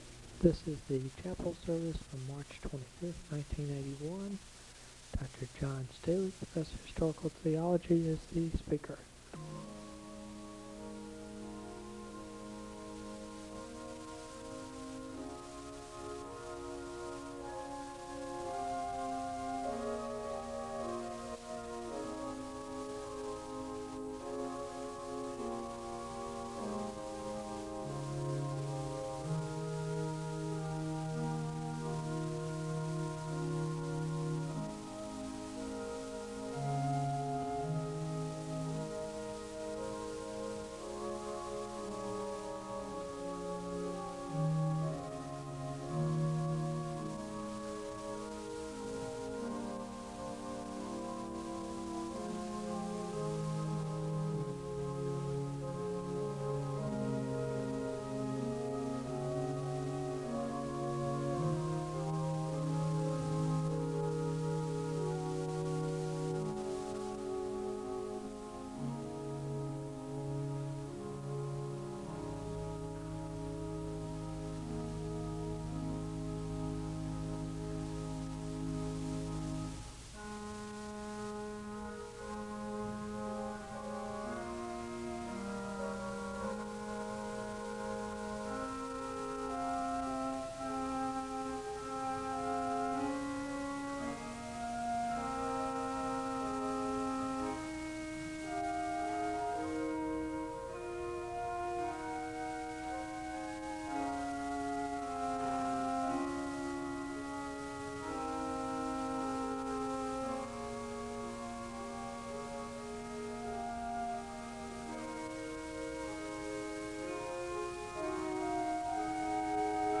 The service begins with organ music (00:00-05:30).
The choir sings a song of worship (11:07-14:00).
Location Wake Forest (N.C.)
SEBTS Chapel and Special Event Recordings SEBTS Chapel and Special Event Recordings